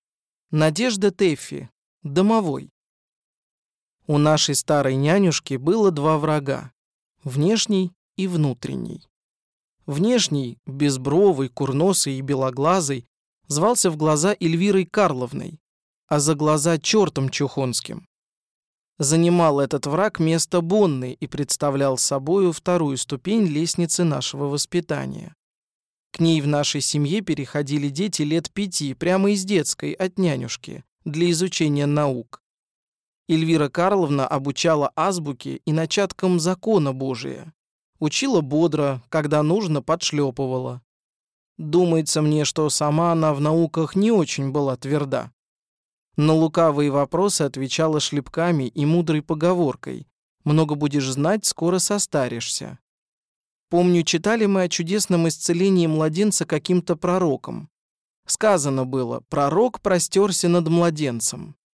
Аудиокнига Домовой | Библиотека аудиокниг